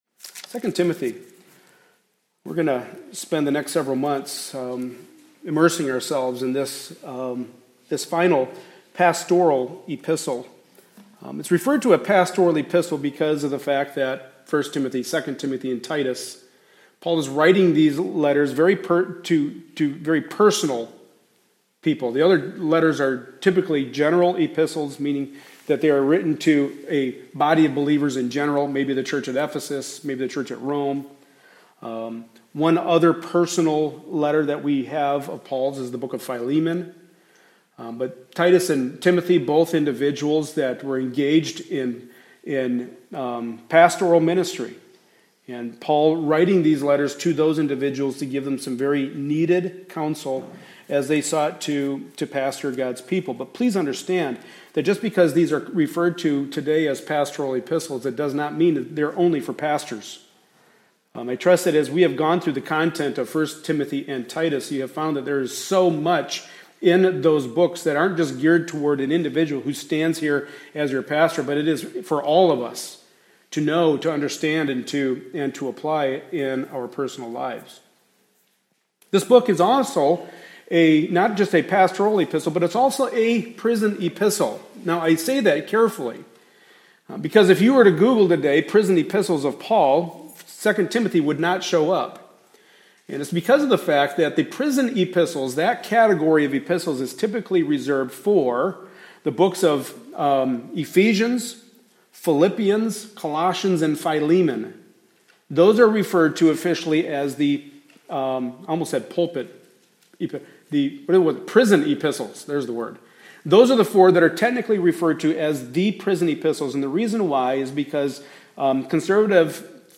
Passage: 2 Timothy 1:1-5 Service Type: Sunday Morning Service